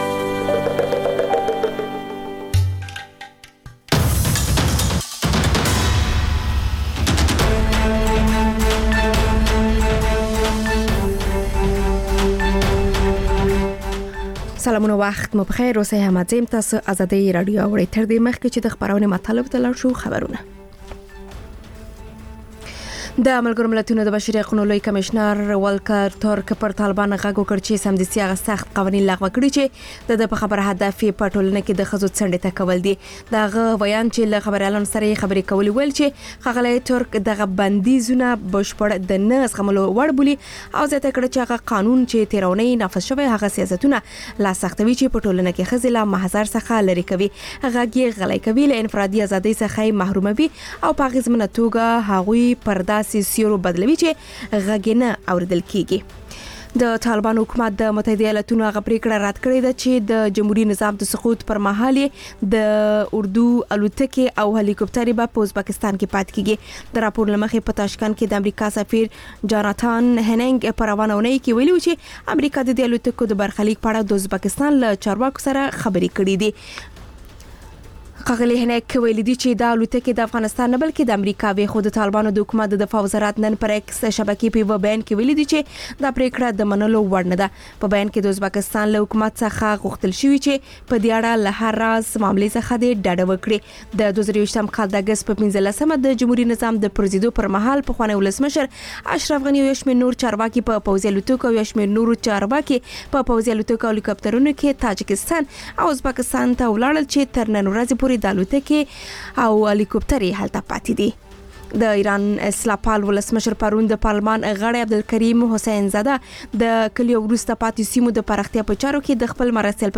خبرونه او راپورونه